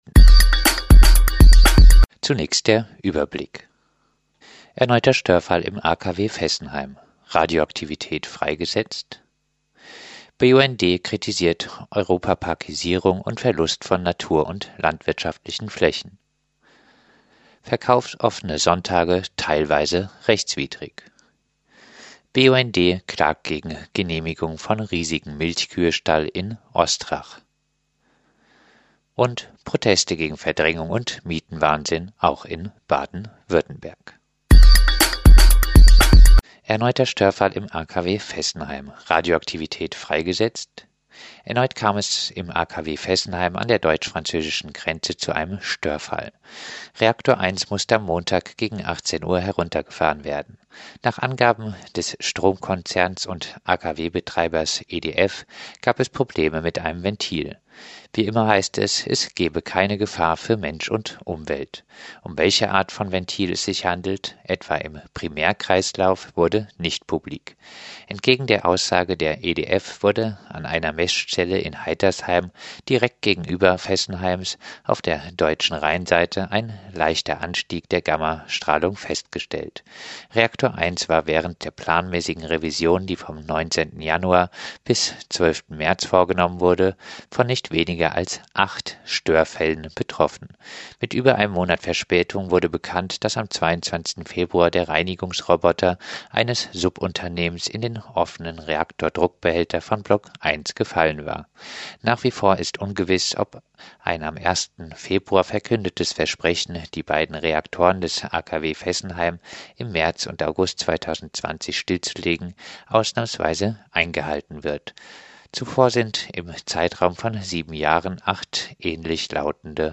Fokus-Südwest-Nachrichten vom 4. April 2019